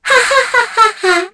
Kara-Vox_Happy3_kr.wav